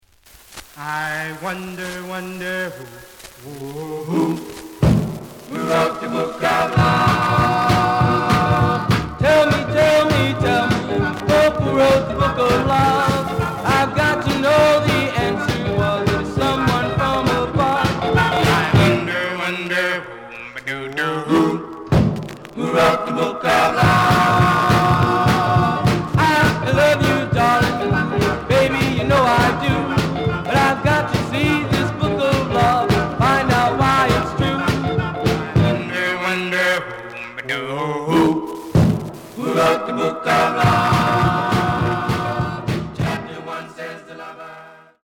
The listen sample is recorded from the actual item.
●Genre: Rhythm And Blues / Rock 'n' Roll
Noticeable noise on parts of A side.)